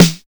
626 SNARE 1.wav